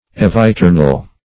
eviternal - definition of eviternal - synonyms, pronunciation, spelling from Free Dictionary
Search Result for " eviternal" : The Collaborative International Dictionary of English v.0.48: Eviternal \Ev`i*ter"nal\, a. [L. eviternus, aeternus.